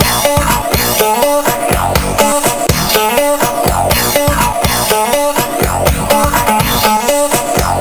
un tempo de 123 BPM